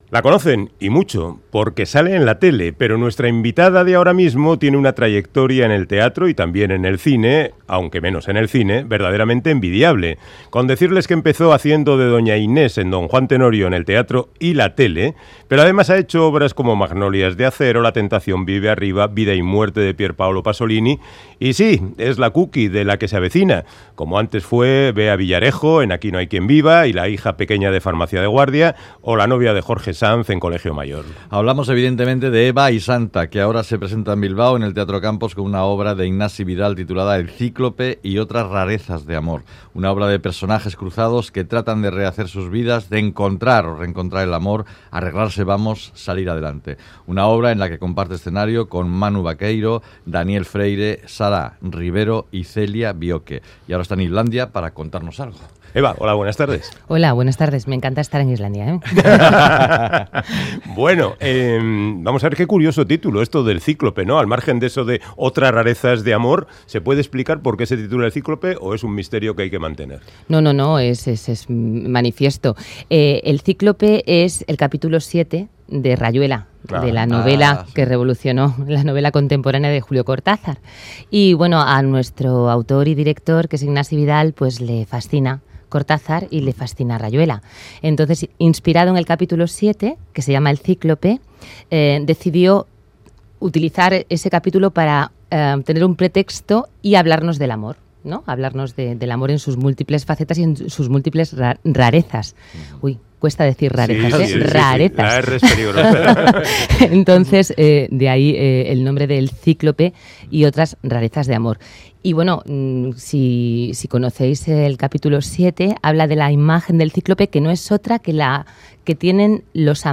Audio: Charlamos con Eva Isanta, famosa por ser la Cuqui en la serie de televisión La que se avecina.